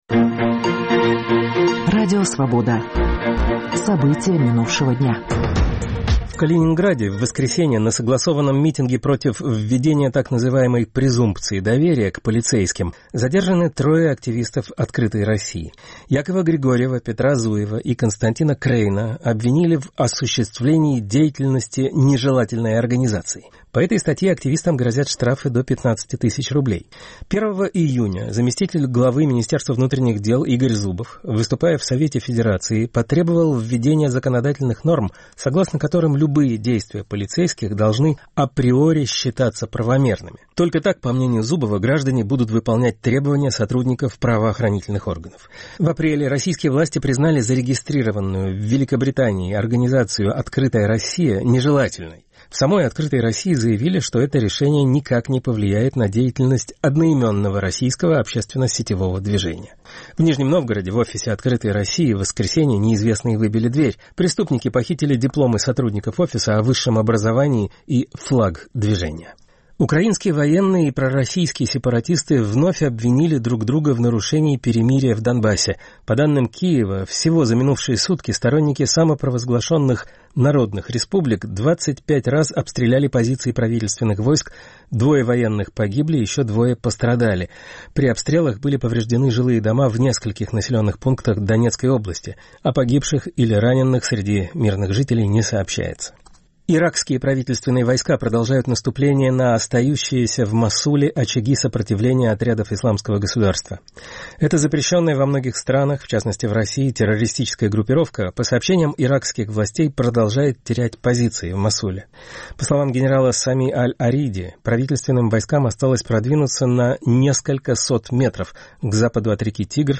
Новости Радио Свобода: итоговый выпуск